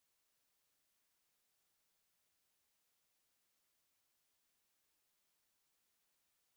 ambience_bluejay.ogg